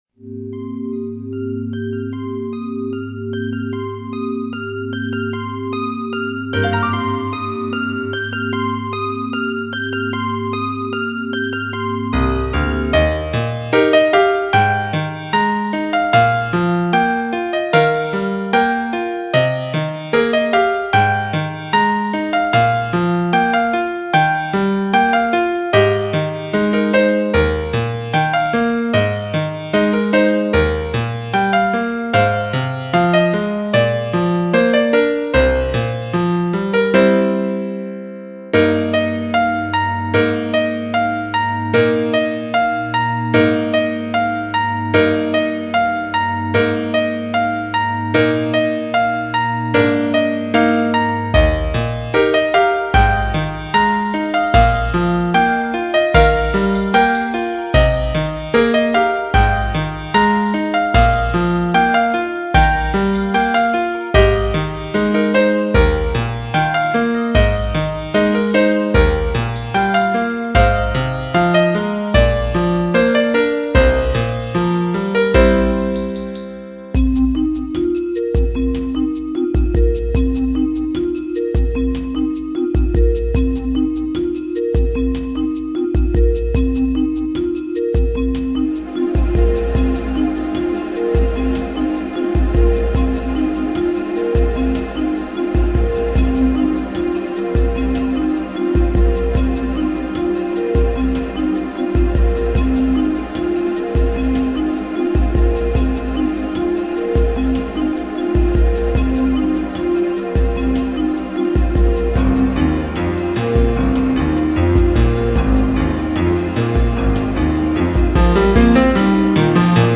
ピアノ音が最大に生きる曲なのに、私が使っている音楽ツールのピアノ音は、かなりヘボいので厳しかったです。
これらの曲は、全てドリームキャストを使って作りました。